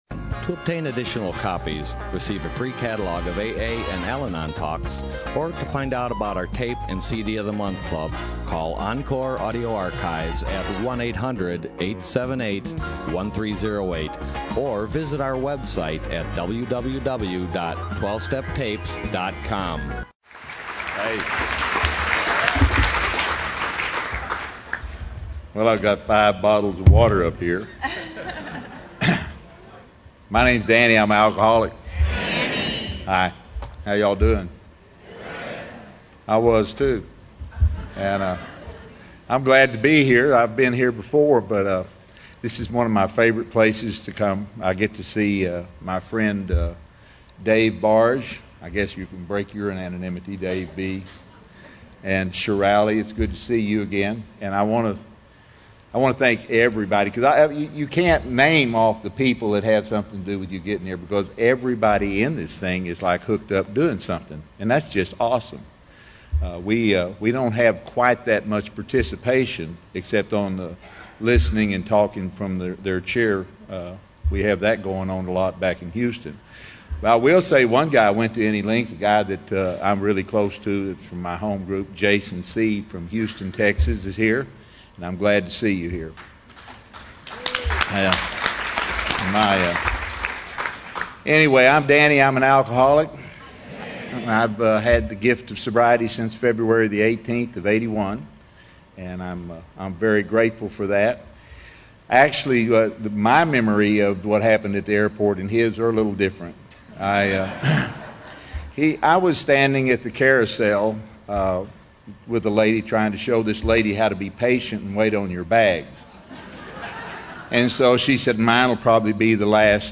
SOUTHBAY ROUNDUP 2015